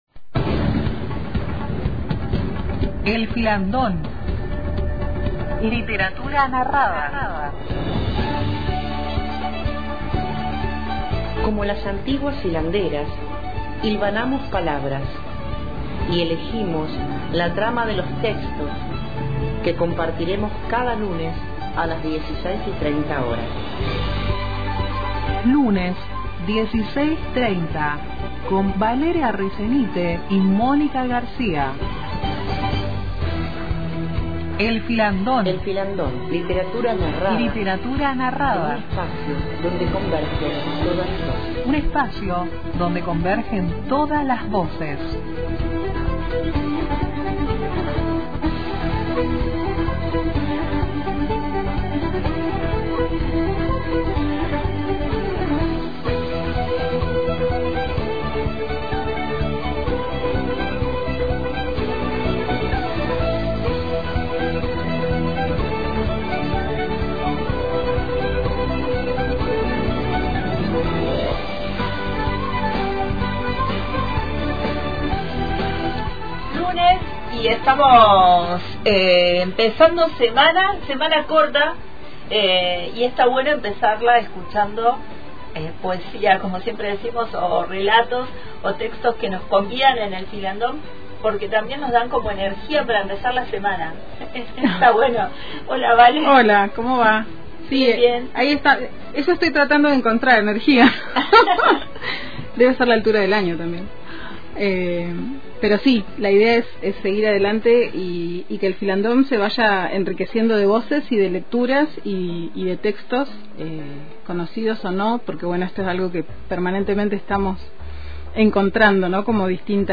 En el encuentro de hoy recorremos la literatura narrada a través de dos voces, una de ellas ya conocida en el espacio y otra que resulta ser nueva.